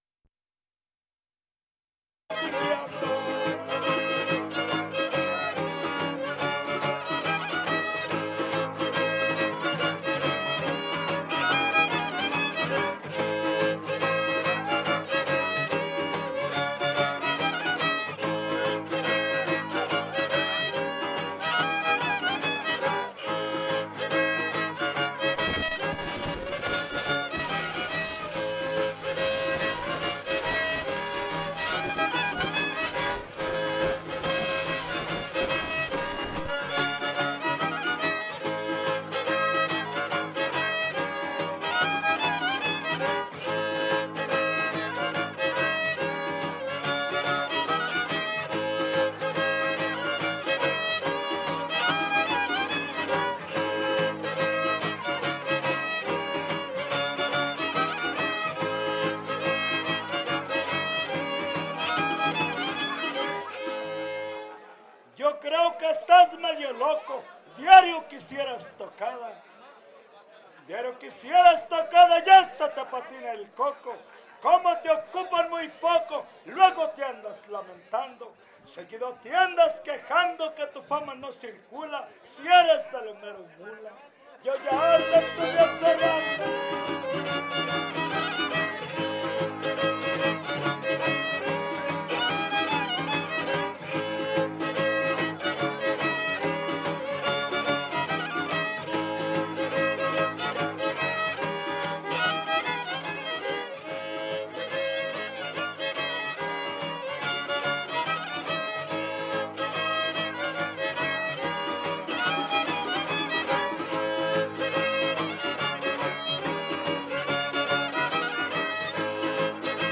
Décima Poesía popular Huapango arribeño
(violín primero)
Violín Vihuela Guitarra
Topada ejidal: Cárdenas, San Luis Potosí